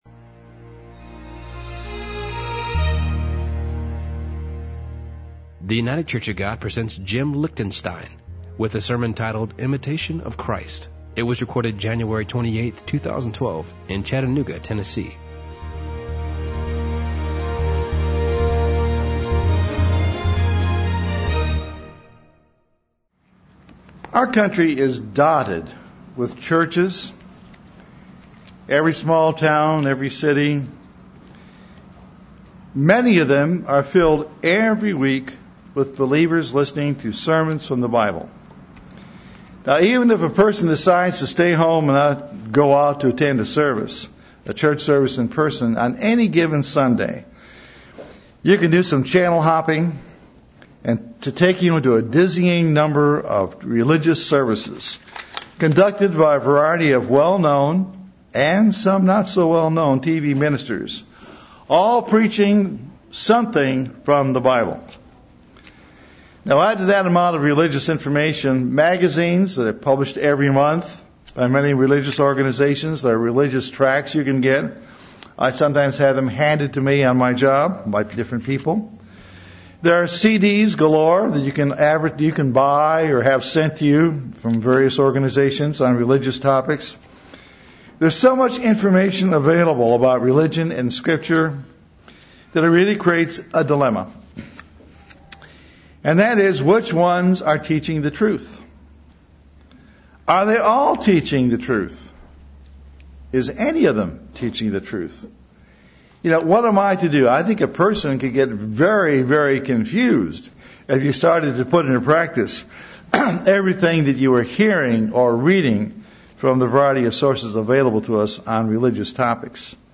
Given in Chattanooga, TN